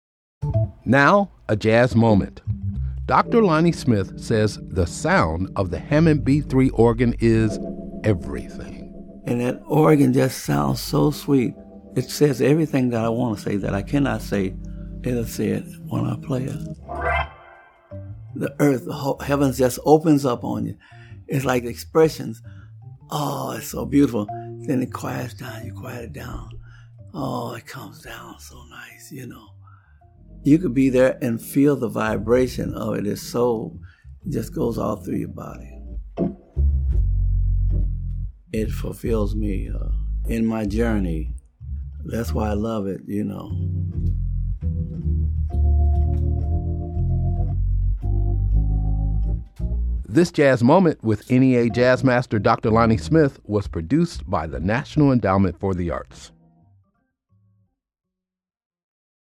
Excerpt of “Too Damn Hot” composed and performed live by Dr. Lonnie Smith, used by permission of DRLS Music.